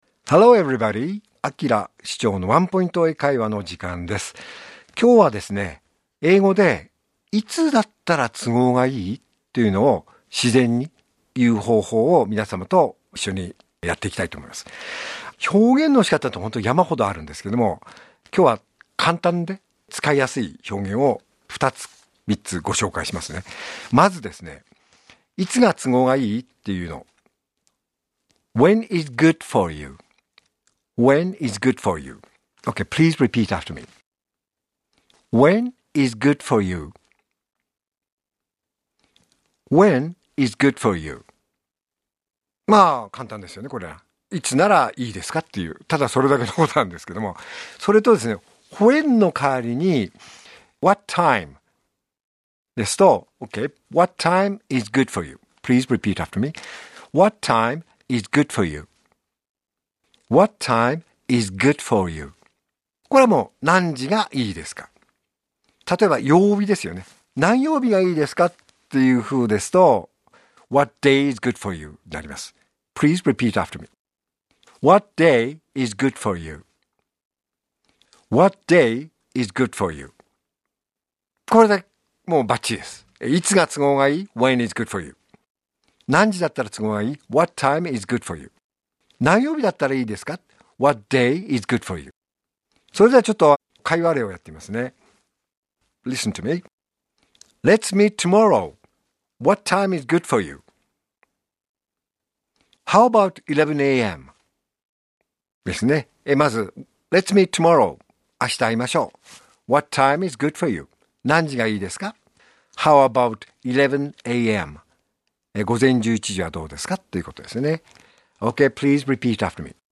R5.4 AKILA市長のワンポイント英会話